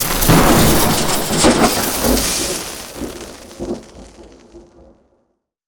elec_lightning_magic_spell_01.wav